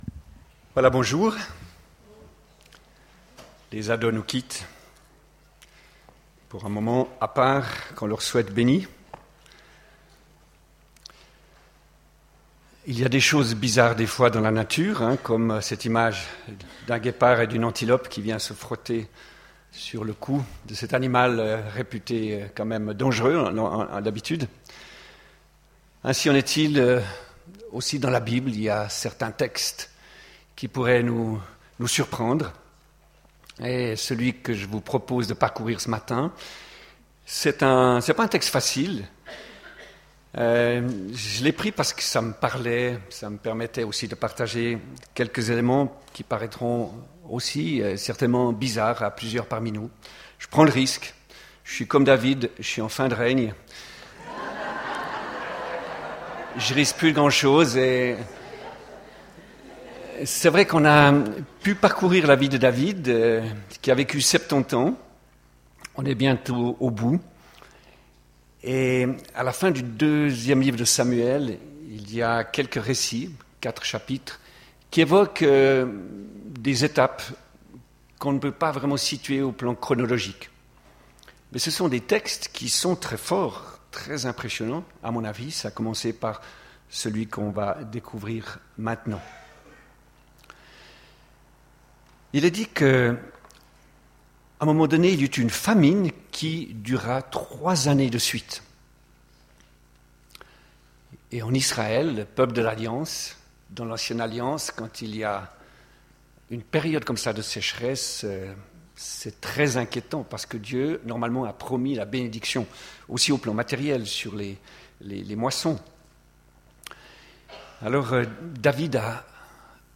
Culte du 14 juin 2015